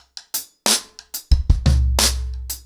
ROOTS-90BPM.29.wav